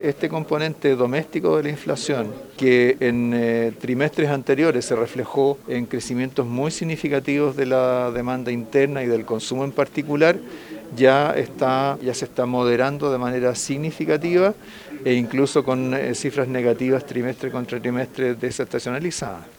El análisis del ministro Marcel se dio en medio del Encuentro Nacional del Transporte de Carga, donde el tema de los costos logísticos y la inflación se tomó las conversaciones.